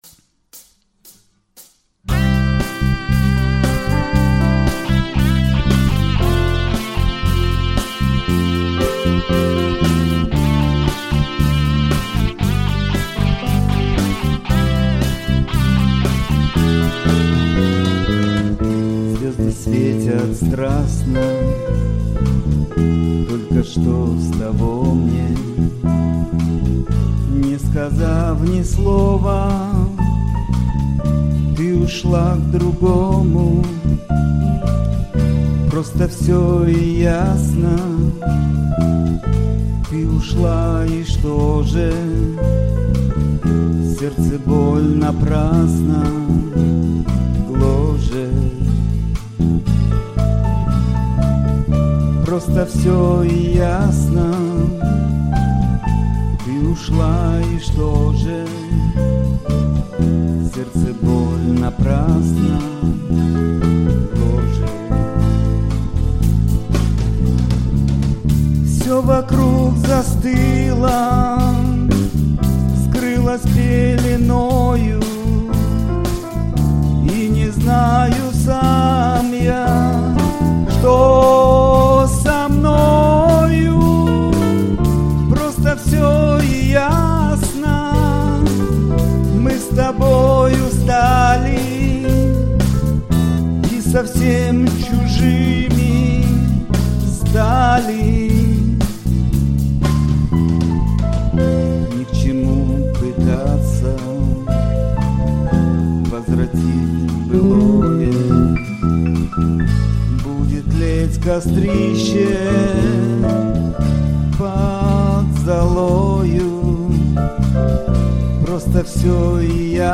Запись май 2012